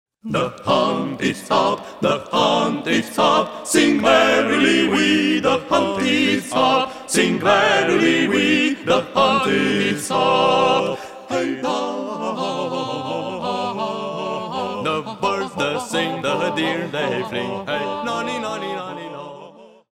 madrigals composed during the Renaissance
This is vocal music that belongs to the soul.